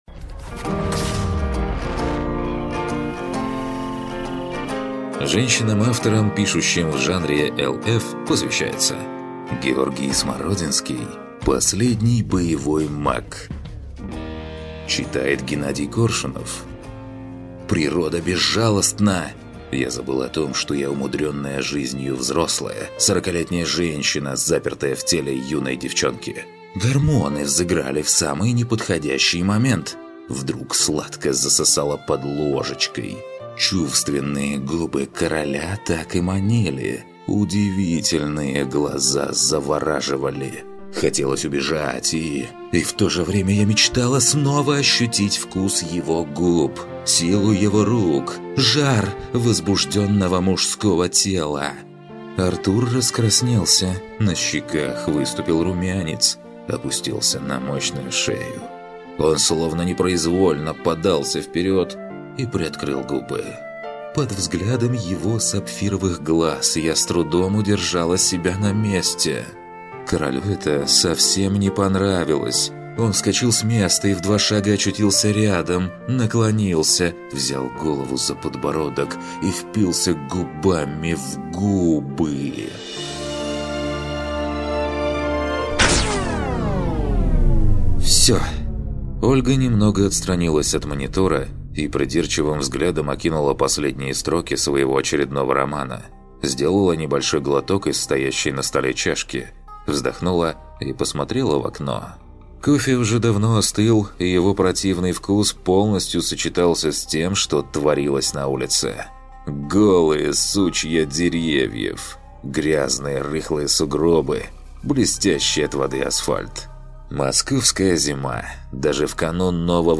Аудиокнига Последний Боевой маг | Библиотека аудиокниг